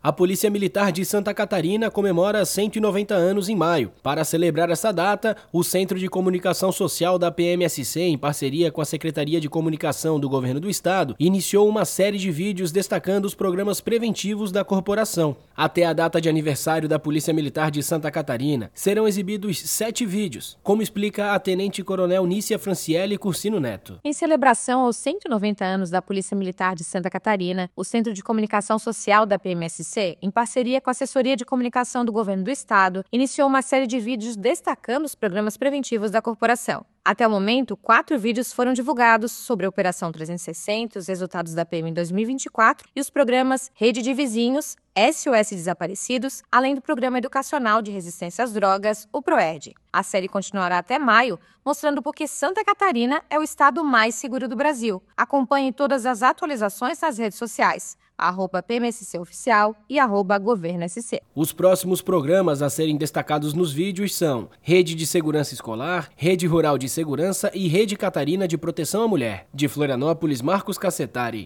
BOLETIM – Polícia Militar de Santa Catarina comemora 190 anos com série de vídeos sobre programas preventivos